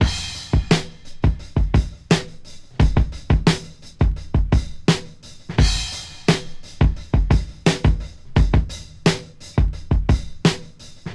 • 86 Bpm Fresh Breakbeat Sample F Key.wav
Free breakbeat - kick tuned to the F note. Loudest frequency: 904Hz
86-bpm-fresh-breakbeat-sample-f-key-ESv.wav